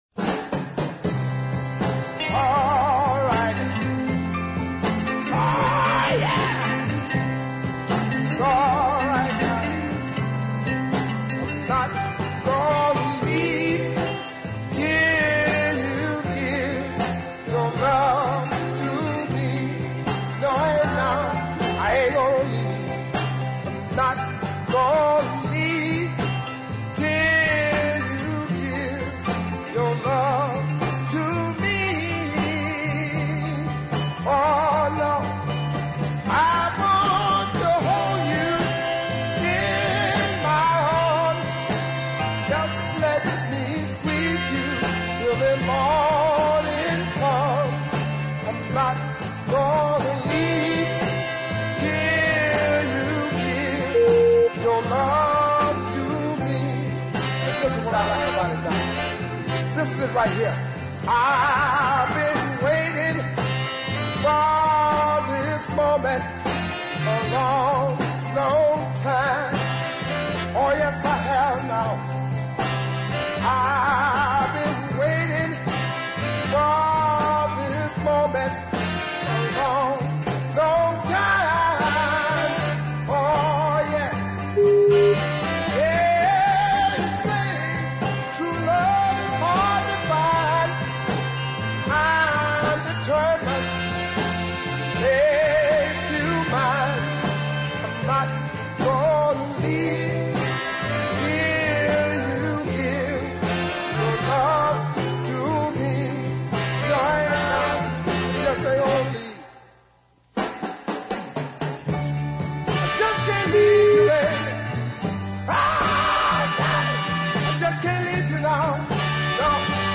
Both toe-tapping sides of his “screamer” 45 Listen